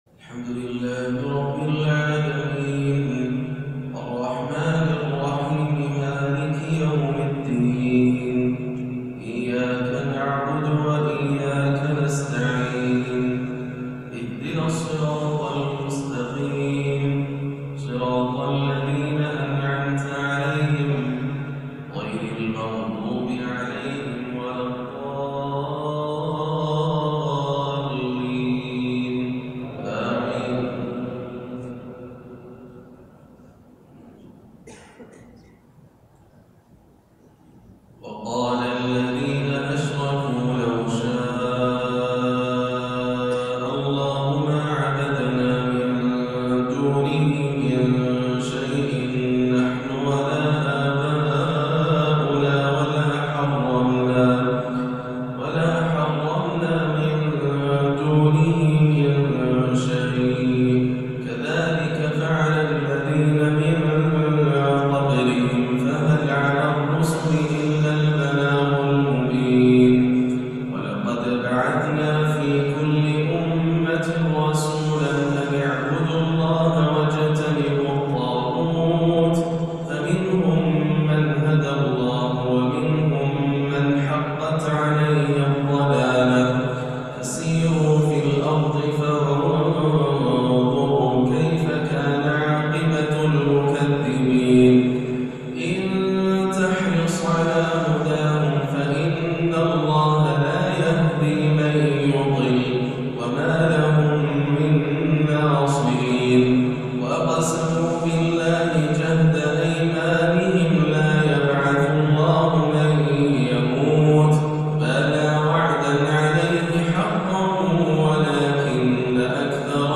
فجر الأربعاء 7-1-1439هـ من سورة النحل 35-64 > عام 1439 > الفروض - تلاوات ياسر الدوسري